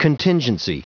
Prononciation du mot contingency en anglais (fichier audio)
Prononciation du mot : contingency